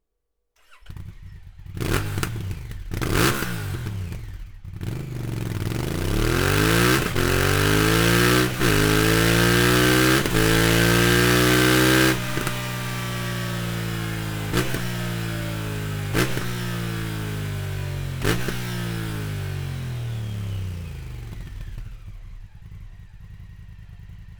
Akrapovic Slip-On Line (Titanium) Endschalldämpfer mit Titan-Hülle, mit EU-Zulassung; für
Ein tieferer und klangvollerer Sound unterstreicht die Charakteristik des 270° Zweizylinders der Honda und bietet einen pulsierenden Beat.
Sound Akrapovic Slip-On